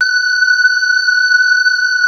Index of /90_sSampleCDs/Wizoo - Powered Wave/PPG CHOIR